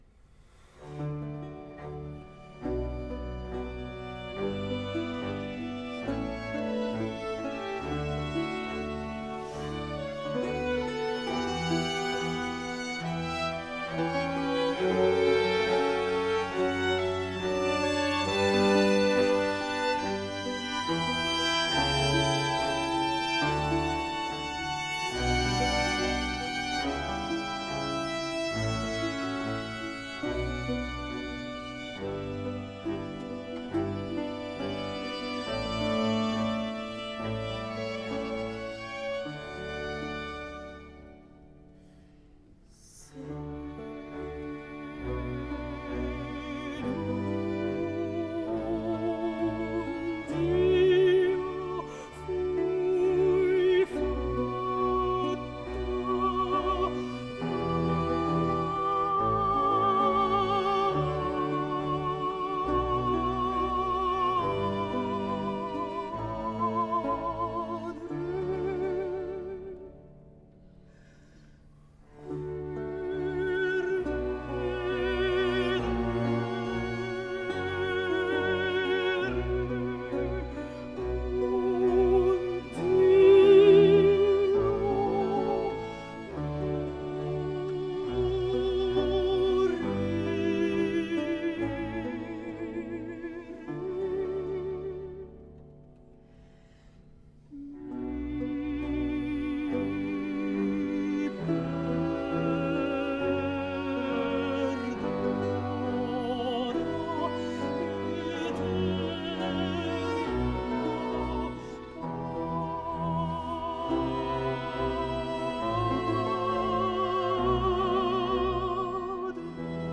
Знаменитая кавантина с речитативом